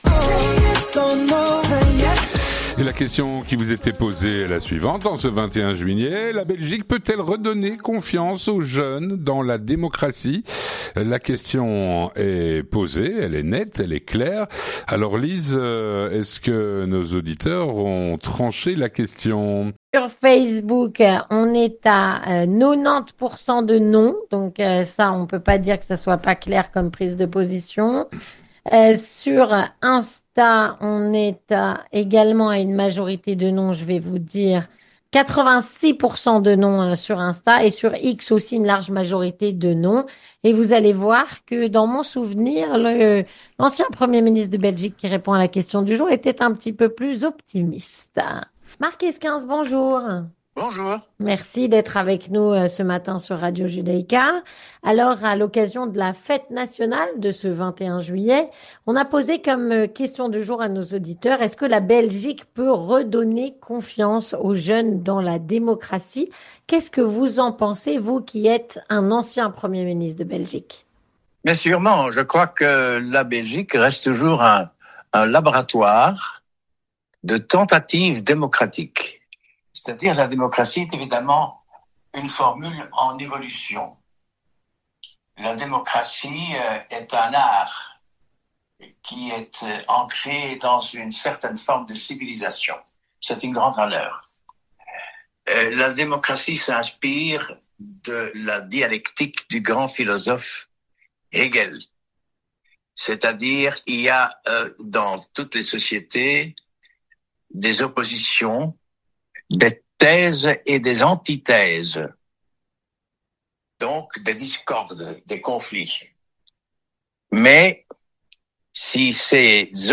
Mark Eyskens, ancien Premier Ministre et Ministre d'État, répond à "La Question Du Jour".